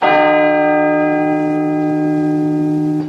• BELL TOLL BIG.wav
BELL_TOLL_BIG_RQH.wav